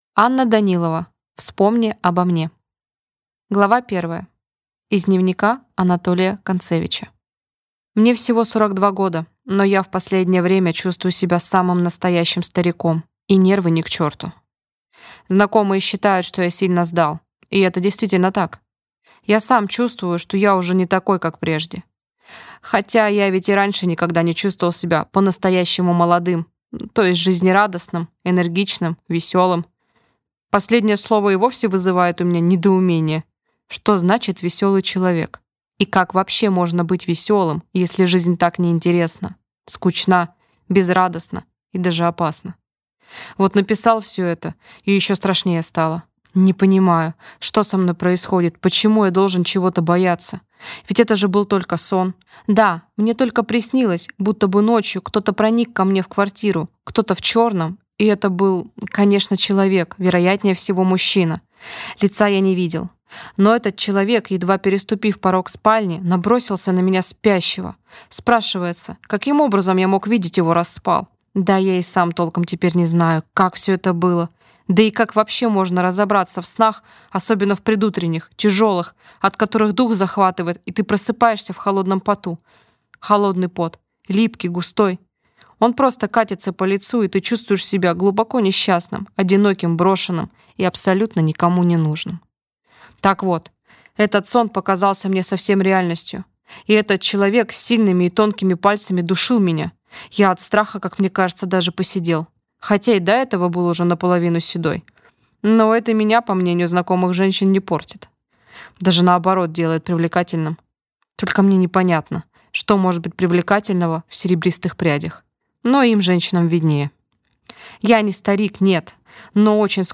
Аудиокнига Вспомни обо мне | Библиотека аудиокниг
Прослушать и бесплатно скачать фрагмент аудиокниги